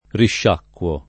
[ rišš # kk U o ]